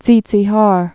(tsētsēhär)